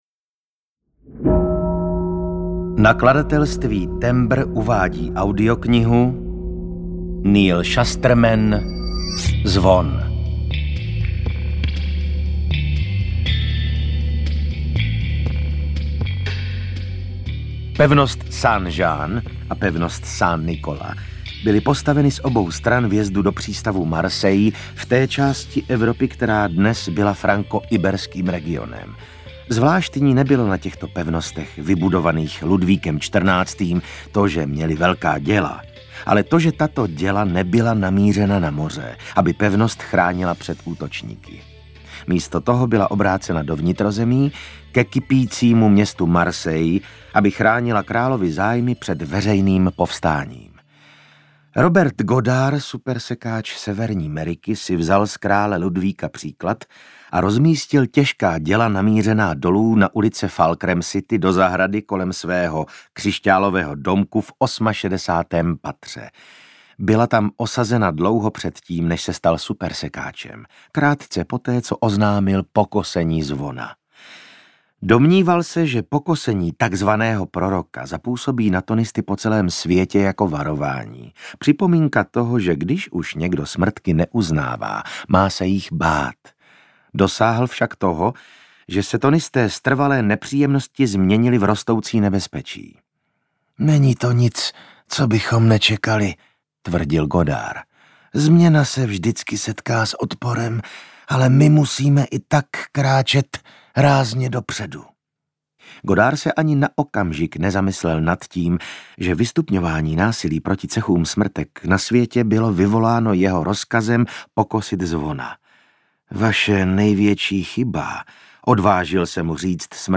Zvon audiokniha
Ukázka z knihy
• InterpretVasil Fridrich, Jana Stryková